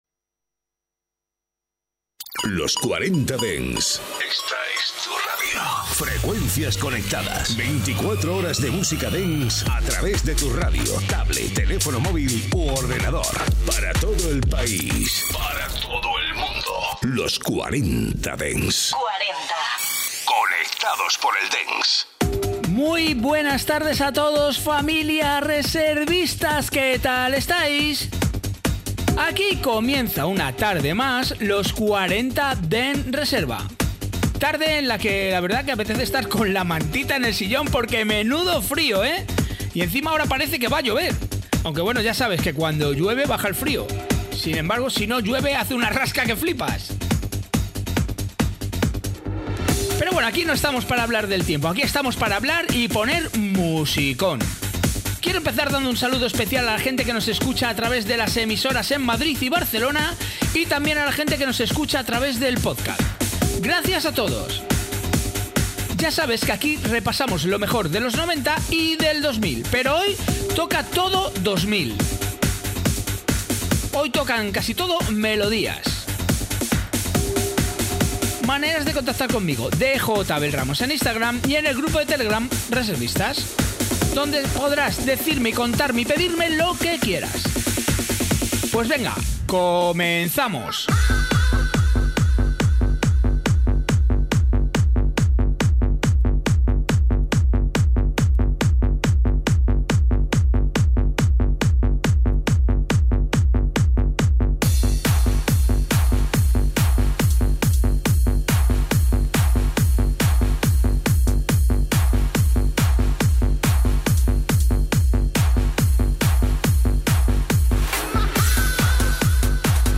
Escucha todos los temazos clásicos y míticos de la música dance de las últimas décadas